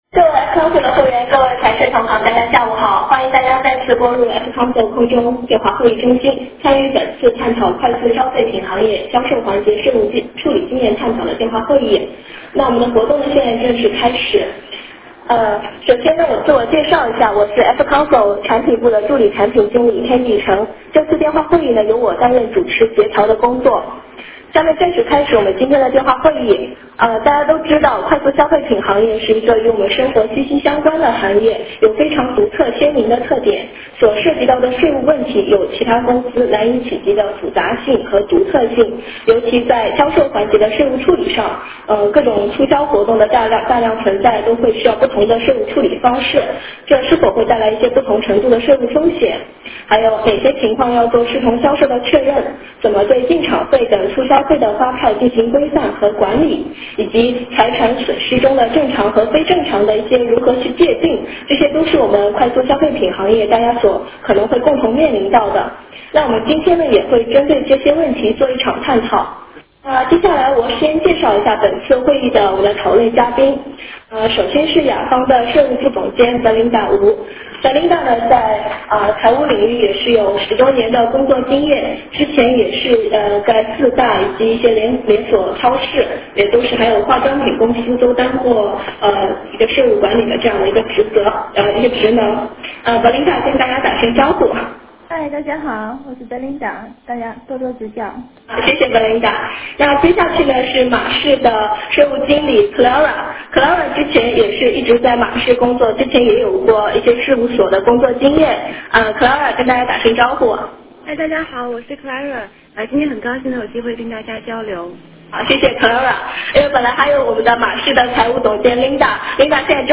FMCG行业互助式电话会议探讨最佳实践处理方式